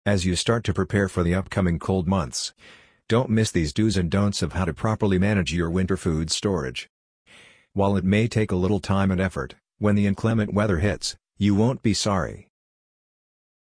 amazon_polly_86992.mp3